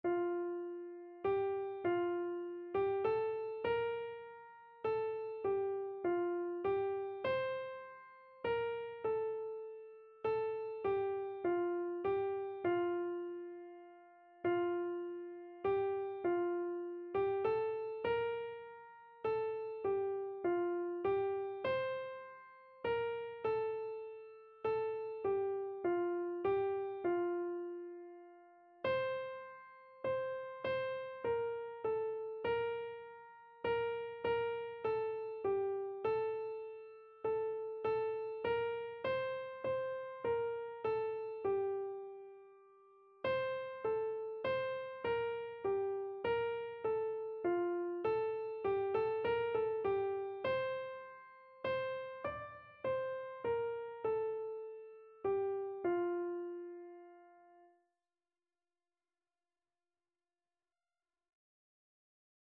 Classical Prichard, Rowland H Alleluia, Sing to Jesus, Hyfrydol Keyboard version
Christian Christian Keyboard Sheet Music Alleluia, Sing to Jesus, Hyfrydol
Free Sheet music for Keyboard (Melody and Chords)
F major (Sounding Pitch) (View more F major Music for Keyboard )
3/4 (View more 3/4 Music)
Keyboard  (View more Easy Keyboard Music)
Classical (View more Classical Keyboard Music)